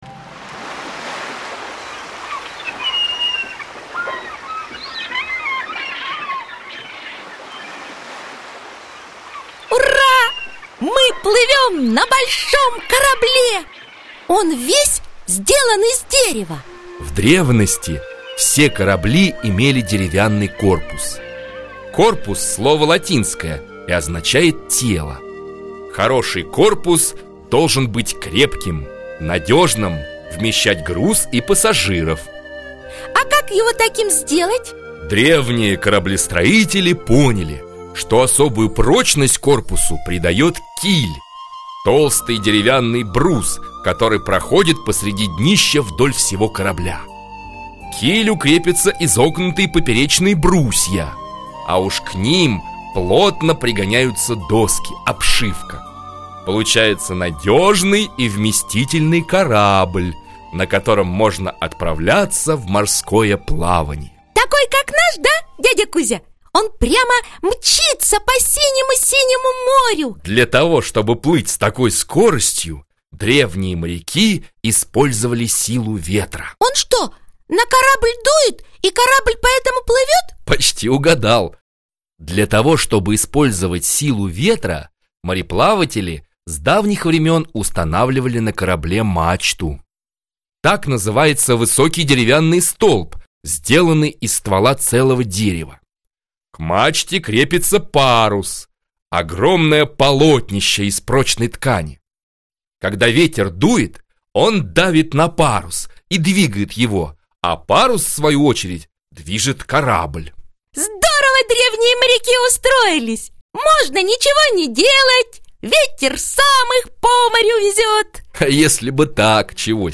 Аудиокнига Корабли и мореплавание | Библиотека аудиокниг